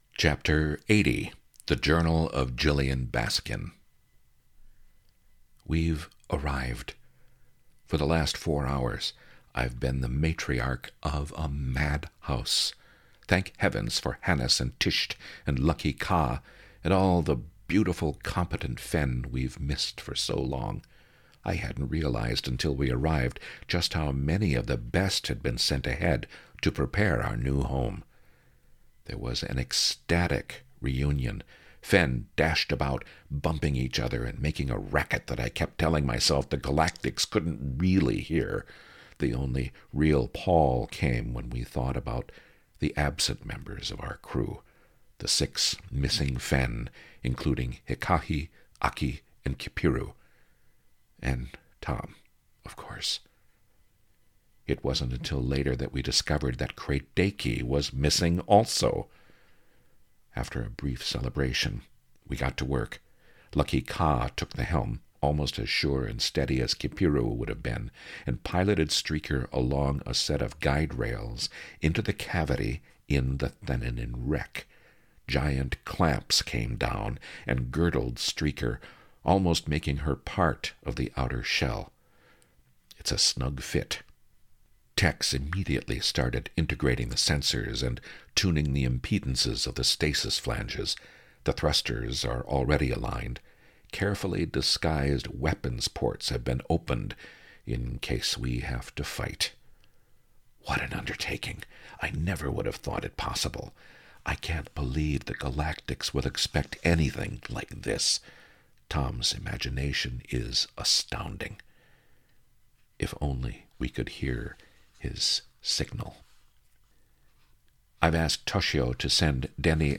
Audio Book